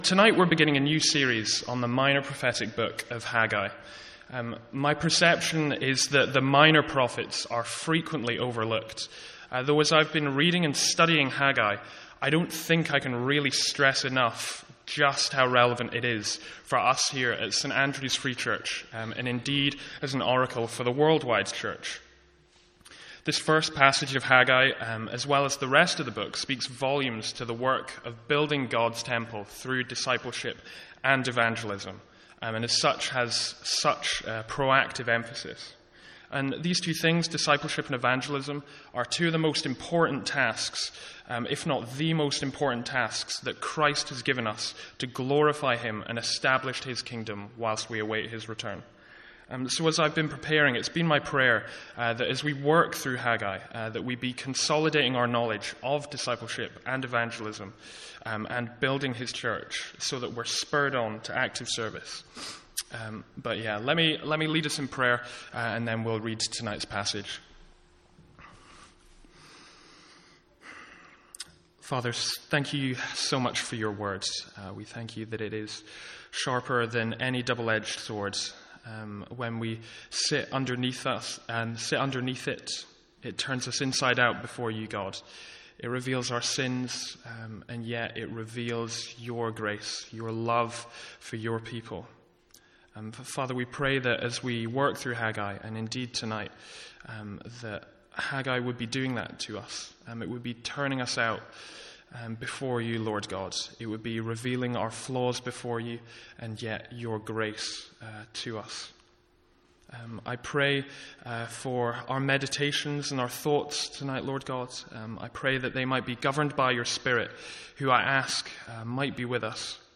Sermons | St Andrews Free Church
From our evening series in Haggai.